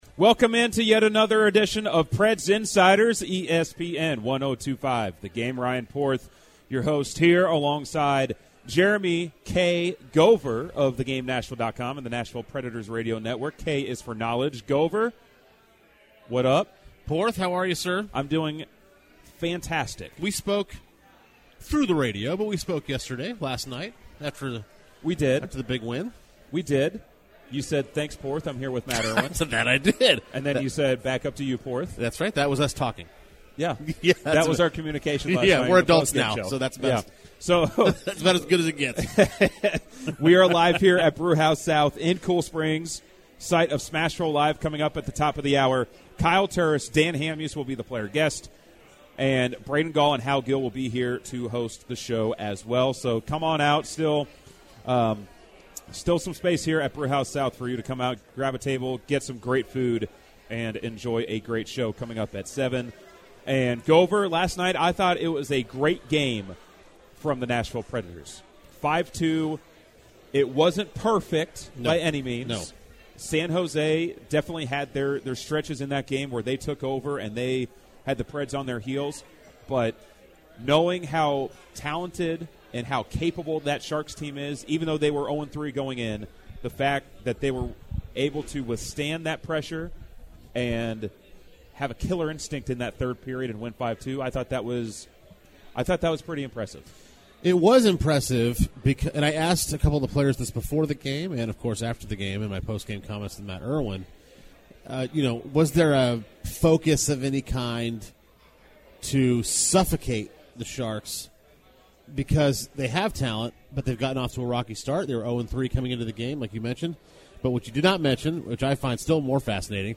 from Brewhouse South in Cool Springs to react to the Predators' 5-2 win vs. San Jose and Roman Josi's contract situation.